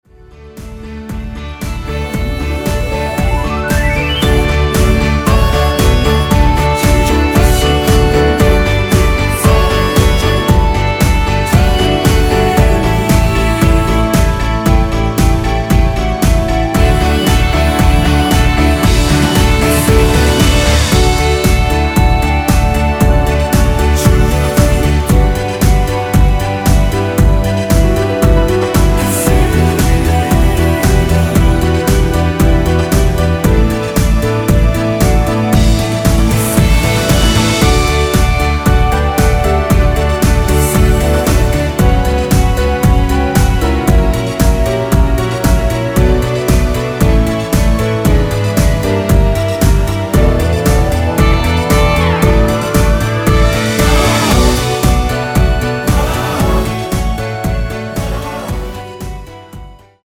엔딩이 페이드 아웃이라 노래 부르기 좋게 엔딩 만들었습니다.
원키에서(-1)내린 멜로디와 코러스 포함된 MR입니다.
◈ 곡명 옆 (-1)은 반음 내림, (+1)은 반음 올림 입니다.
앞부분30초, 뒷부분30초씩 편집해서 올려 드리고 있습니다.